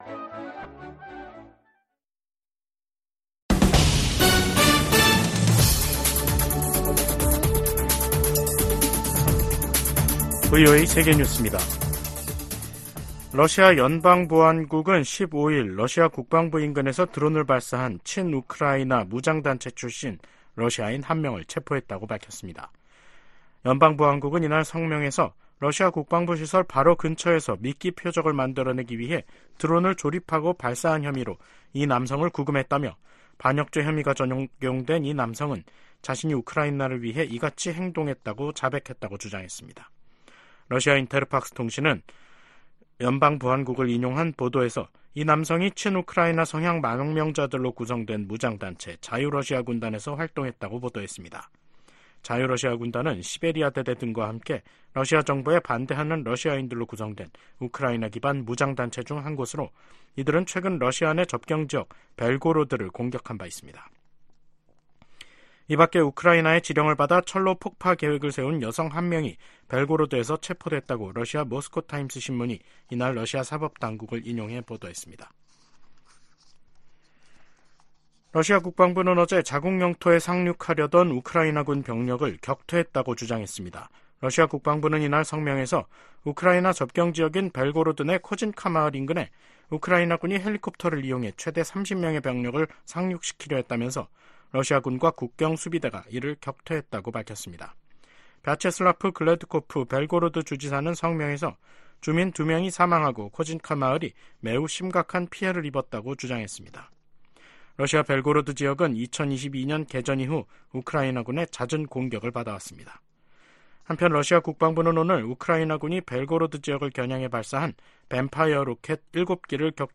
VOA 한국어 간판 뉴스 프로그램 '뉴스 투데이', 2024년 3월 15일 3부 방송입니다. 토니 블링컨 미 국무장관이 한국 주최 제3차 민주주의 정상회의 참석을 위해 서울을 방문합니다. 미 국방부는 한국의 우크라이나 포탄 지원 문제에 관해 우크라이나를 돕는 모든 동맹국을 지지한다는 원론적 입장을 밝혔습니다. 중국 내 탈북민 인권 보호를 위해 미국 정부가 적극적으로 나설 것을 촉구하는 결의안이 하원에서 발의됐습니다.